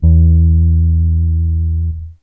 BASS 39.wav